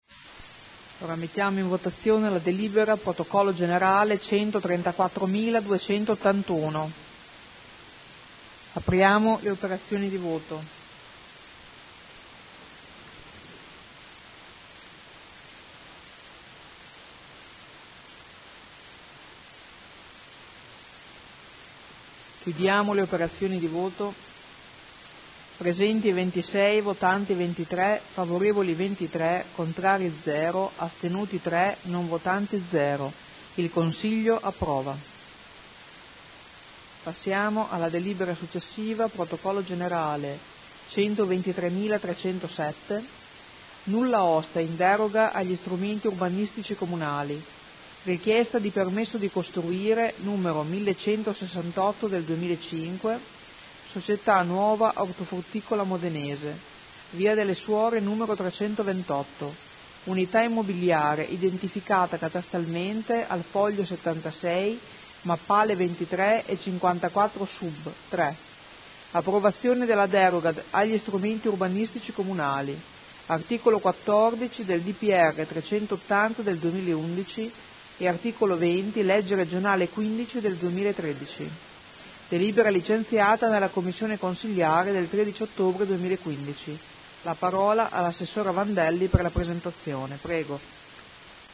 Presidente — Sito Audio Consiglio Comunale
Seduta del 22 ottobre.